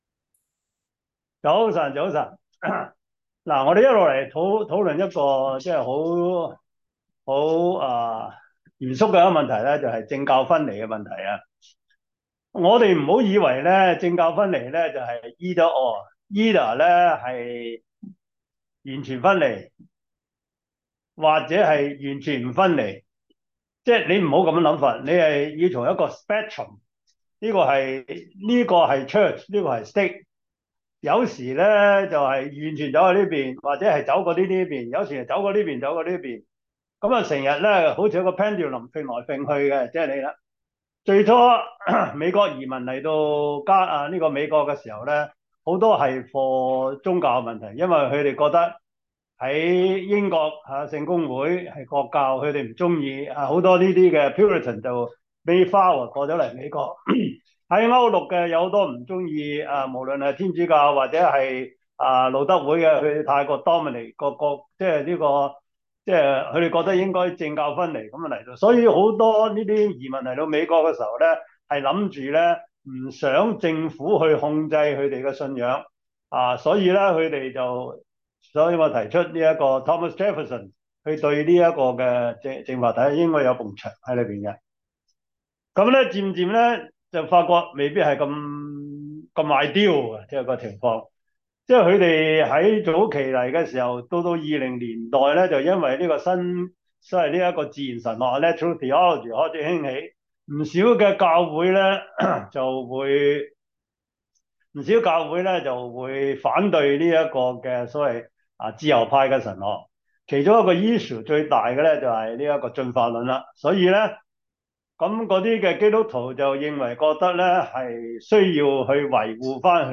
基督教倫理學 Service Type: 中文主日學 基督教倫理學 第十四課